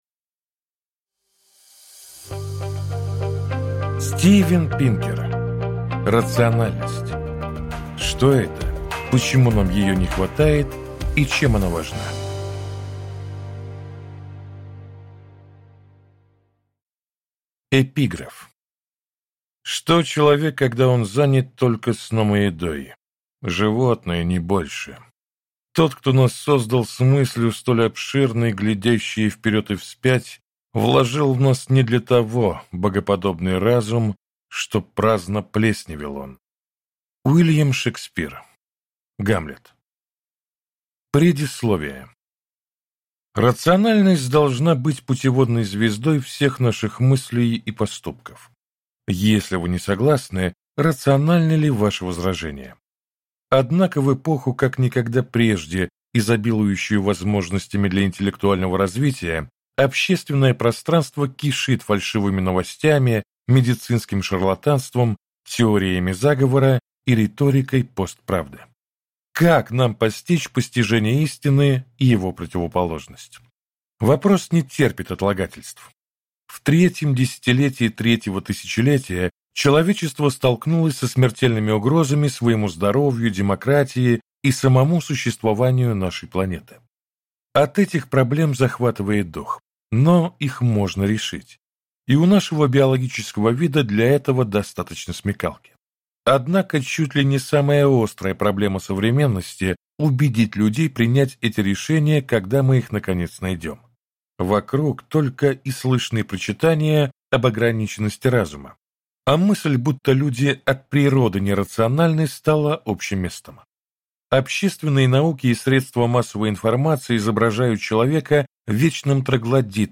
Аудиокнига Рациональность: Что это, почему нам ее не хватает и чем она важна | Библиотека аудиокниг